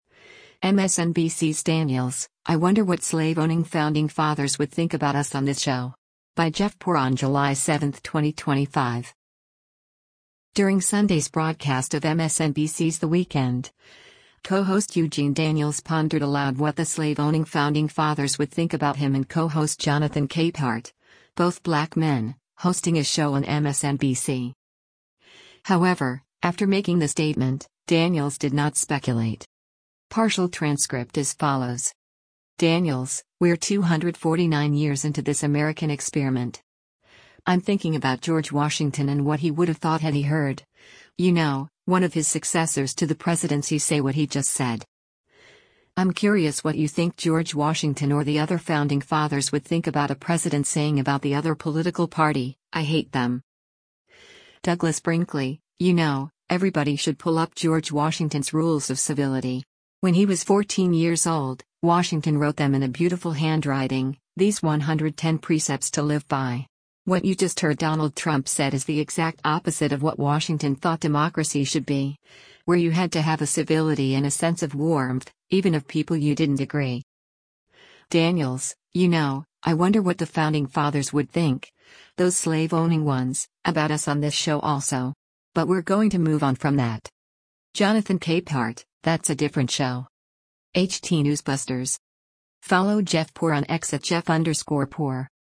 During Sunday’s broadcast of MSNBC’s “The Weekend,” co-host Eugene Daniels pondered aloud what the “slave-owning” Founding Fathers would think about him and co-host Jonathan Capehart, both black men, hosting a show on MSNBC.